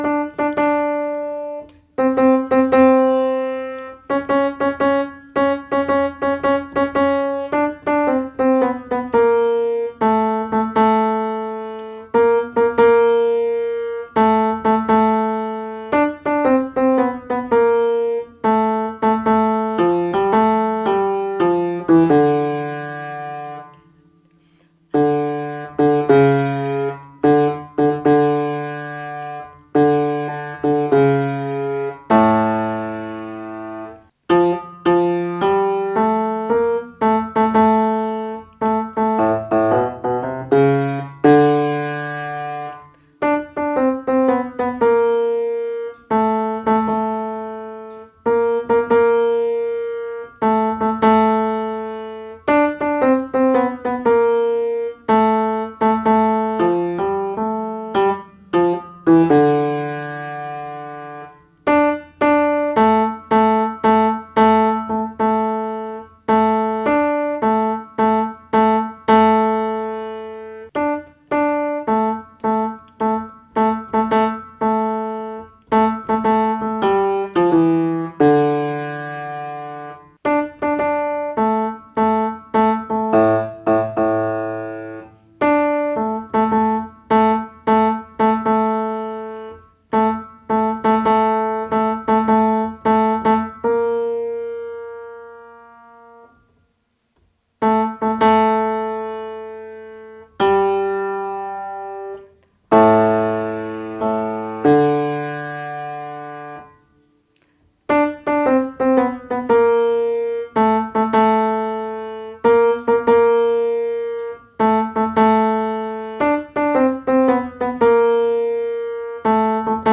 basse
Joshua_Jericho_basse.mp3